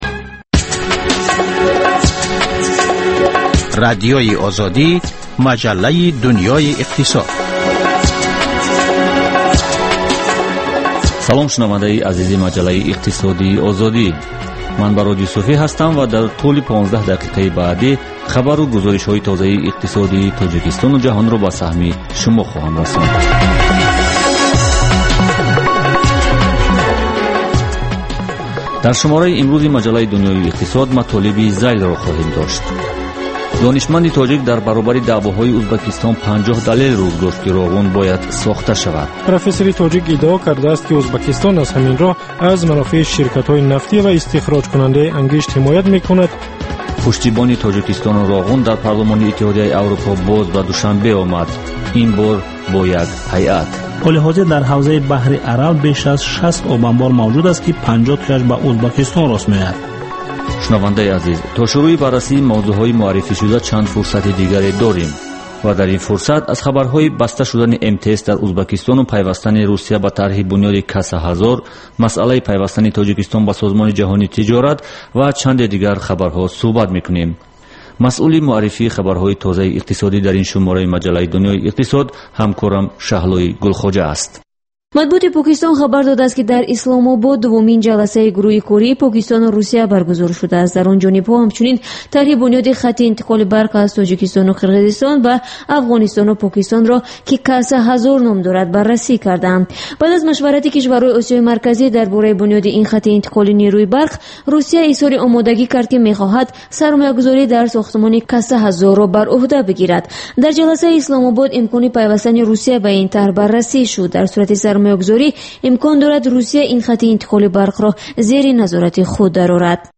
Хабарҳои иқтисодии Тоҷикистон, минтақа ва ҷаҳон. Баррасии фарояндаҳо ва падидаҳои муҳим дар гуфтугӯ бо коршиносон.